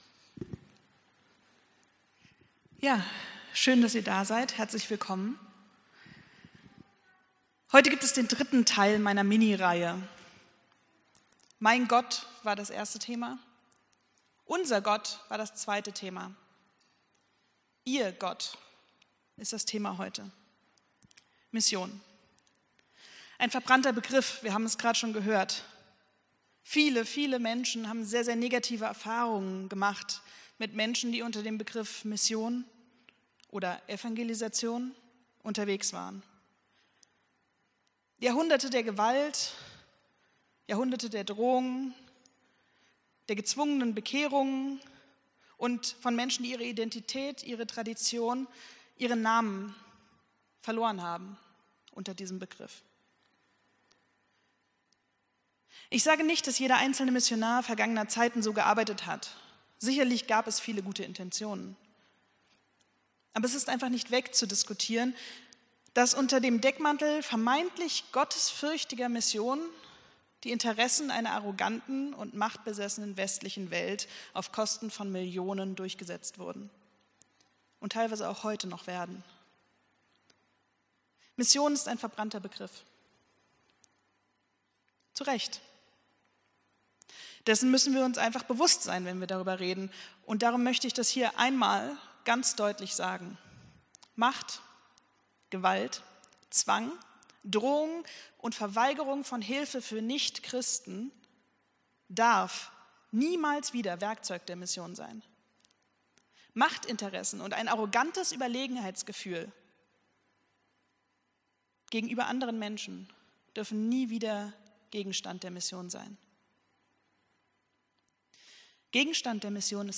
Predigt vom 13.07.2025